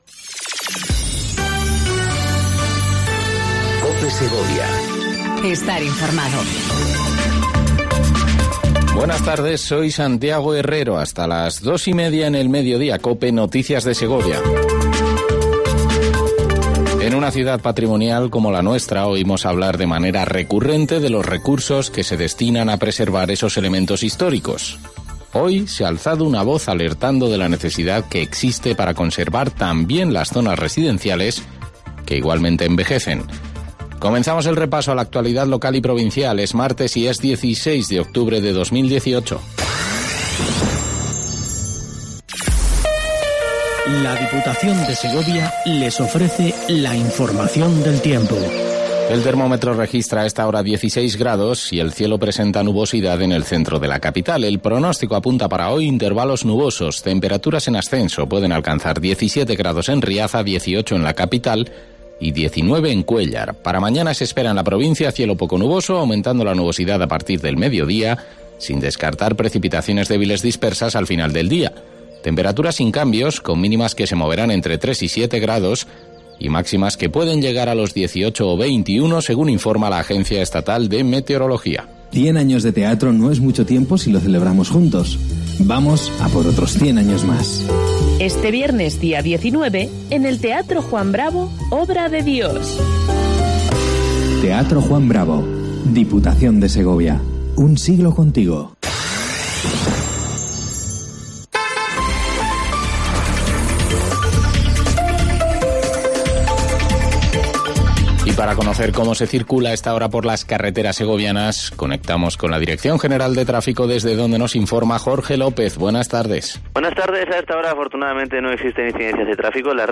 INFORMATIVO MEDIODÍA EN COPE SEGOVIA 14:20 DEL 16/10/18